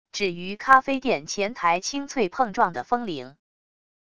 只余咖啡店前台清脆碰撞的风铃wav音频